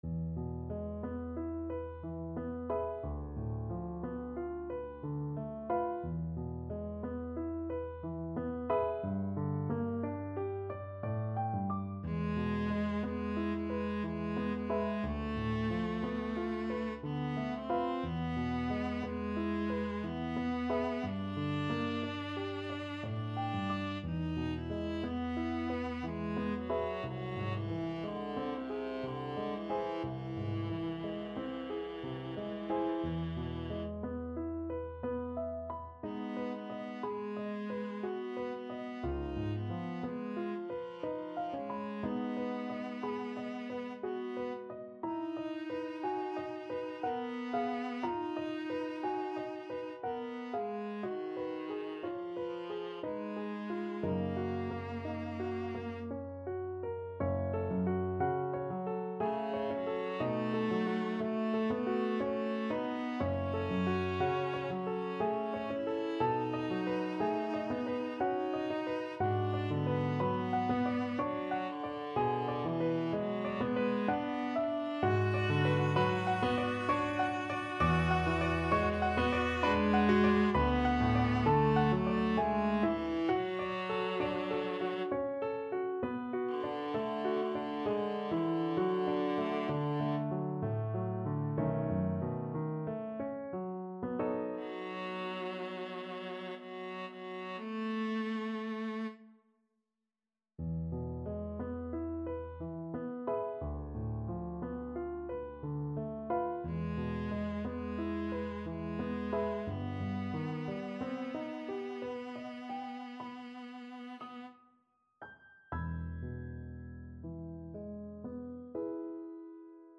Classical Debussy, Claude Beau soir, CD 84 Viola version
Viola
E major (Sounding Pitch) (View more E major Music for Viola )
3/4 (View more 3/4 Music)
Andante ma non troppo =60
Classical (View more Classical Viola Music)